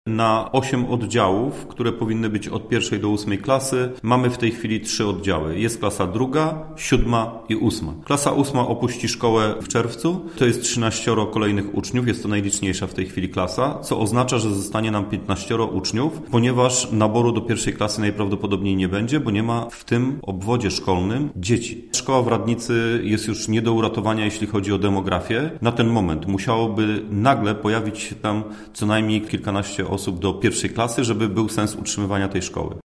– Zmiany demograficzne sprawiły, że szkoła w Radnicy nie ma już racji bytu, a trzeba ją utrzymywać – mówi Marek Cebula, burmistrz Krosna Odrzańskiego.